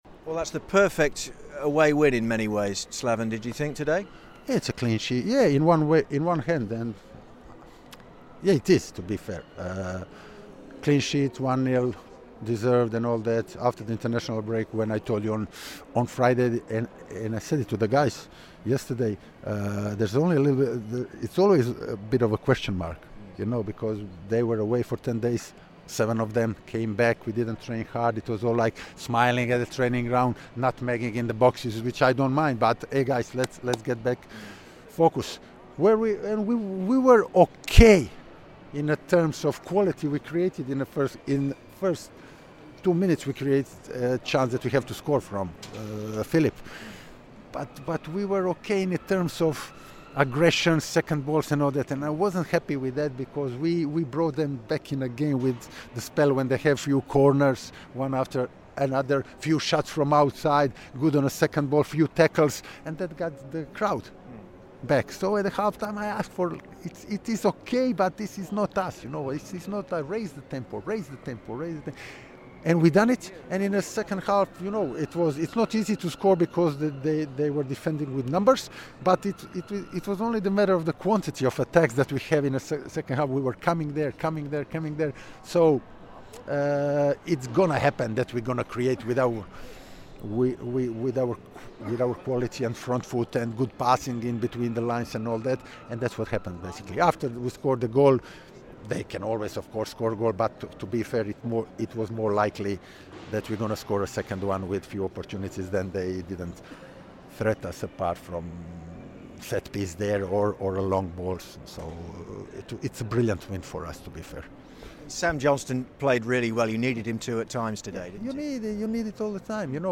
LISTEN: WBA head coach Slaven Bilic reacts to the 1-0 away win at Middlesbrough